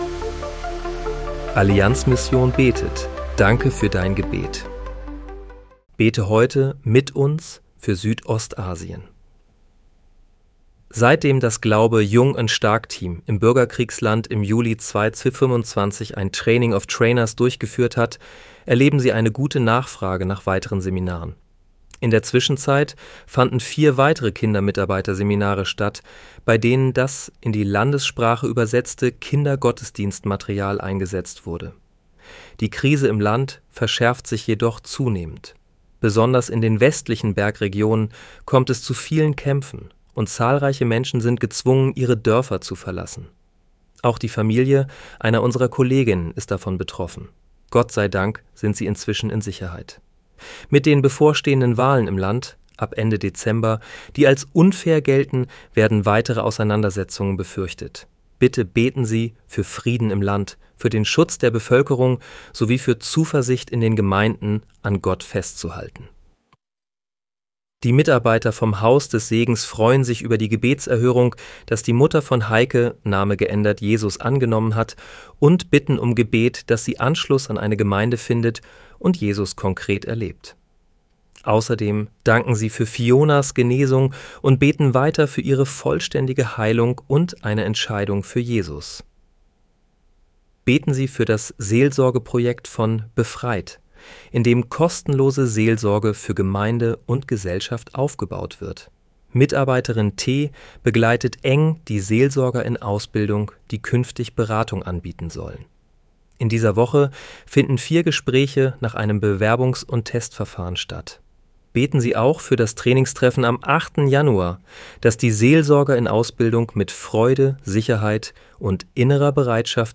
Bete am 06. Januar 2026 mit uns für Südostasien. (KI-generiert mit